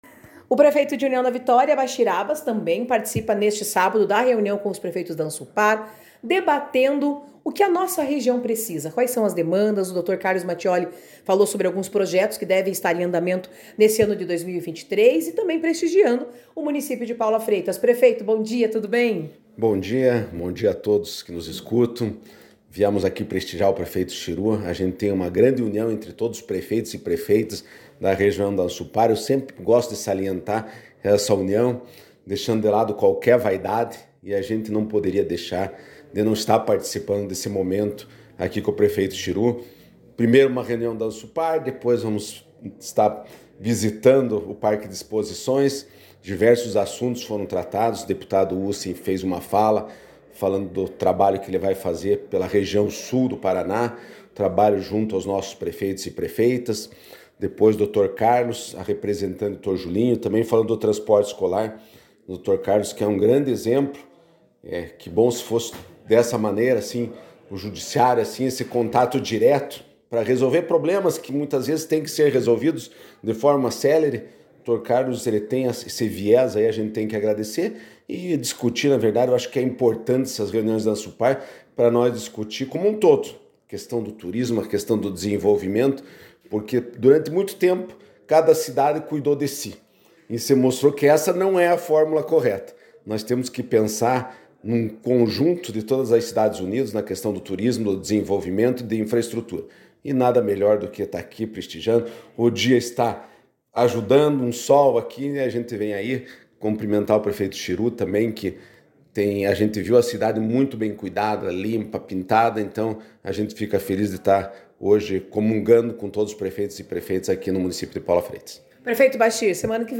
Prefeito de União da Vitória, Bachir Abbas